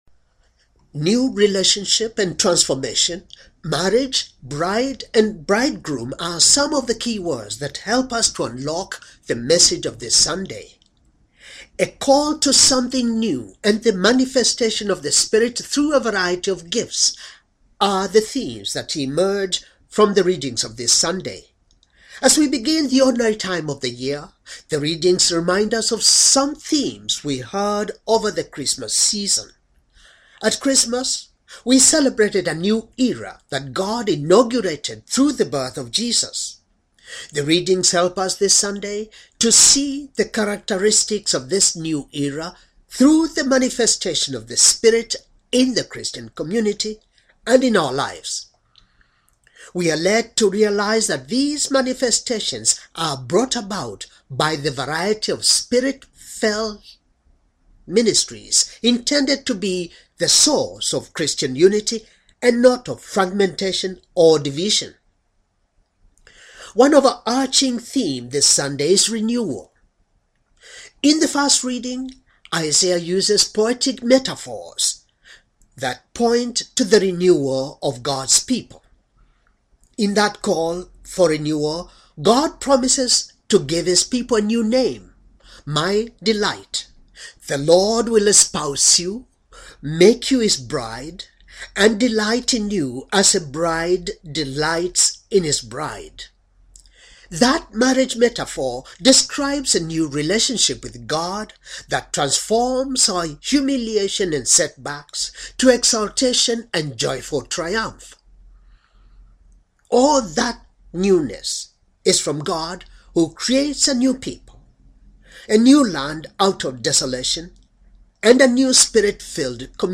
Homily for second Sunday in ordinary time, year c, wedding of cana, first miracle, manifestation